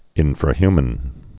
(ĭnfrə-hymən)